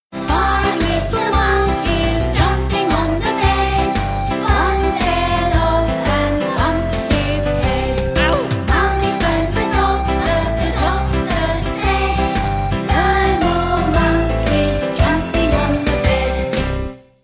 With beautiful illustrations and clearly sung rhymes, Tune into…